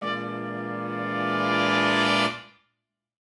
Index of /musicradar/gangster-sting-samples/Chord Hits/Horn Swells
GS_HornSwell-E7b2b5.wav